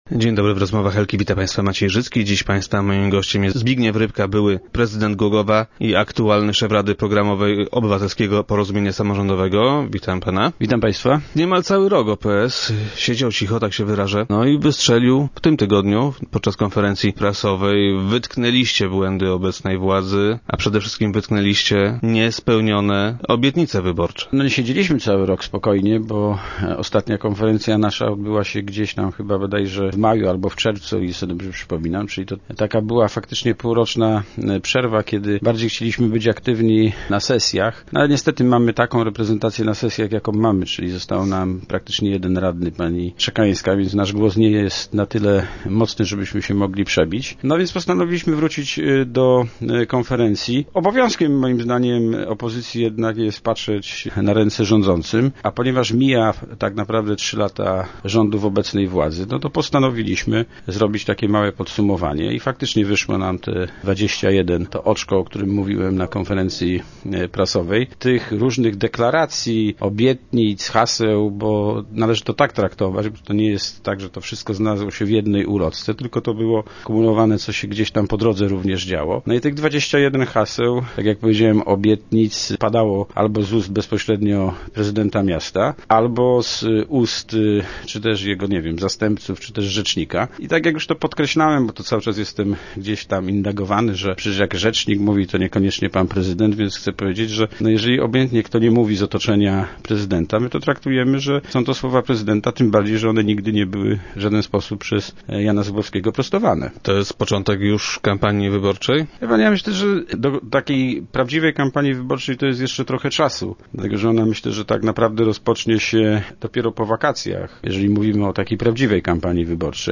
Zbigniew Rybka, szef rady programowej OPS-u wytknął też niespełnione obietnicy wyborcze obecnego prezydenta. Były prezydent Głogowa był gościem Rozmów Elki.